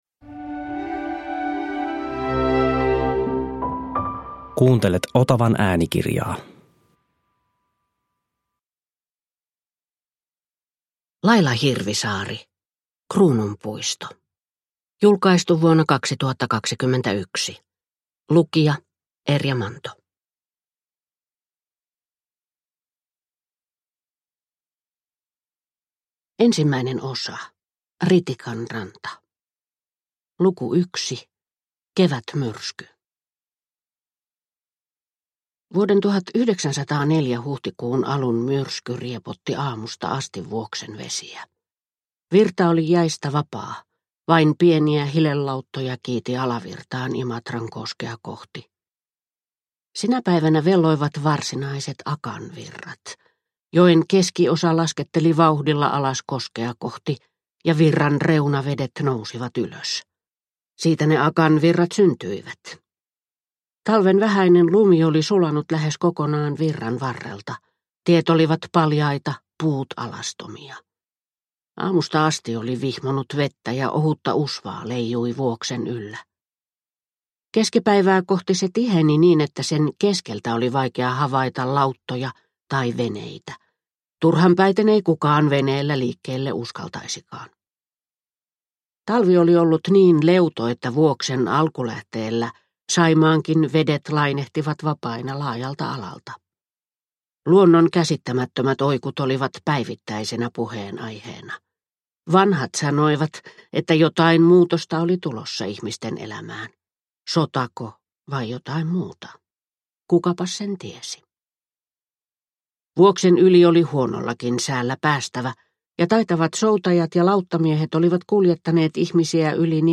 Kruununpuisto – Ljudbok – Laddas ner